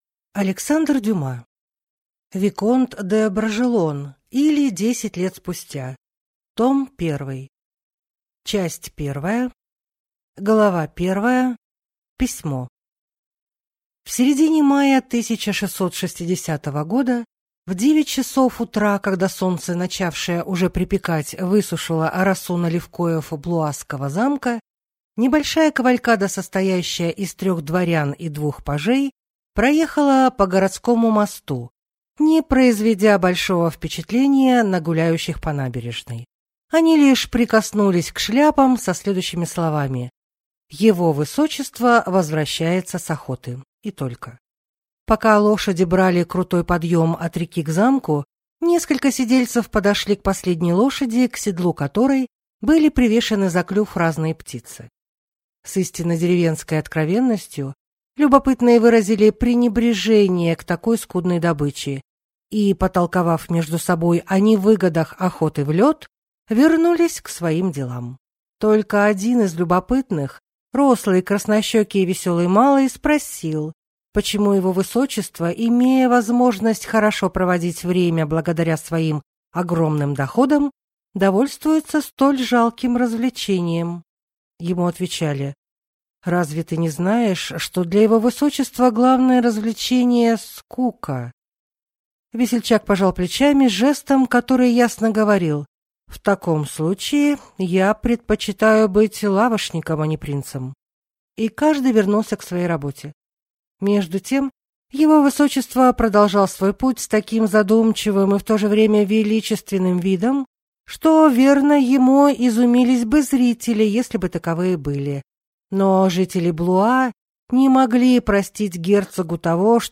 Аудиокнига Виконт де Бражелон, или Десять лет спустя. Том 1 | Библиотека аудиокниг